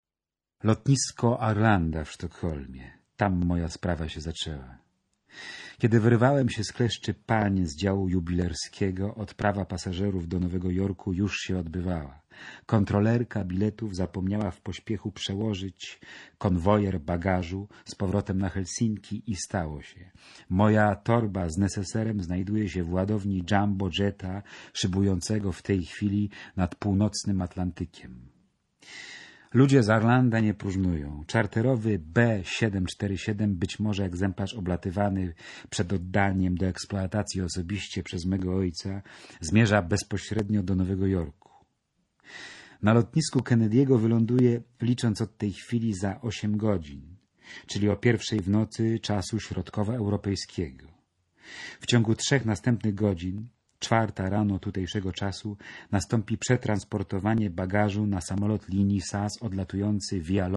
Czyta: Mariusz Bonaszewski